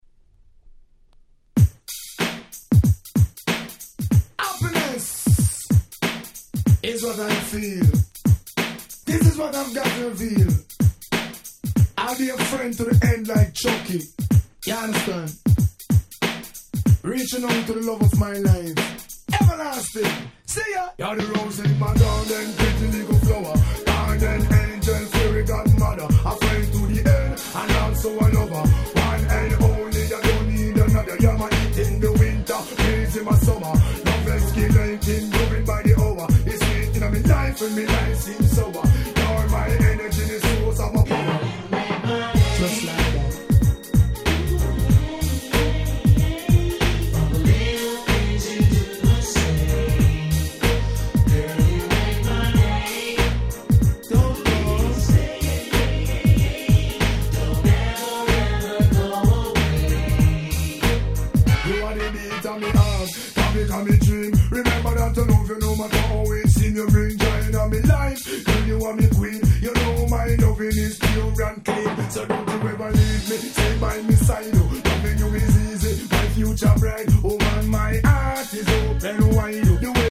93' Nice Ragga Hip Hop !!
サビのボーカルも気持ち良い、非常にSmoothな1曲！